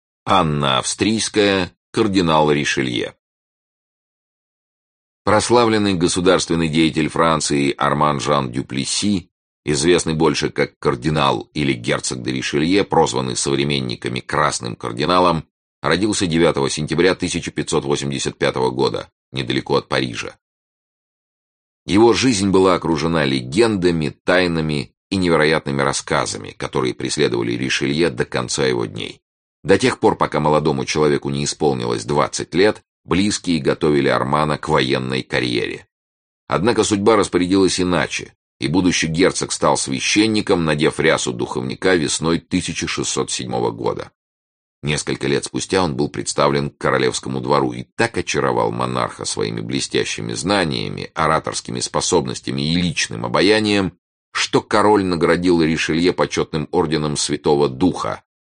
Аудиокнига Великие истории любви | Библиотека аудиокниг
Aудиокнига Великие истории любви Автор Сборник Читает аудиокнигу Сергей Чонишвили.